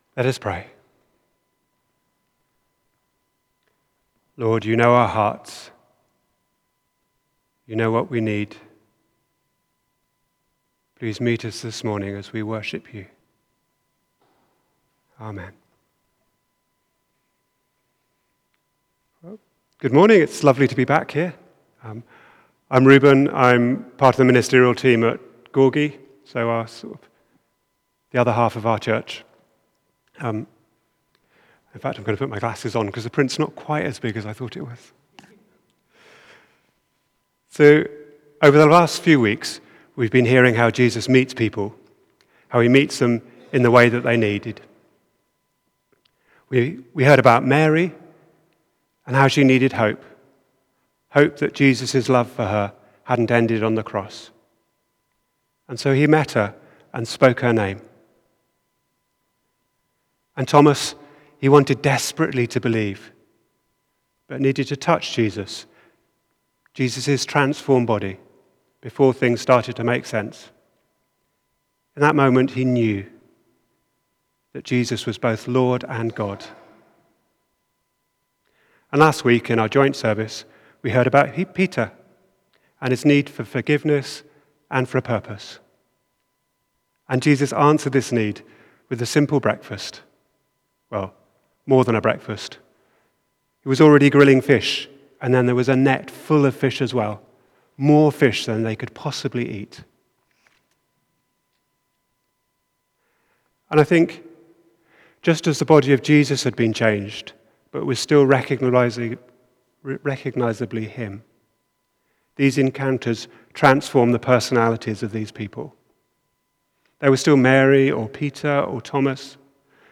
Sunday 28th April 2024 – AM Sermon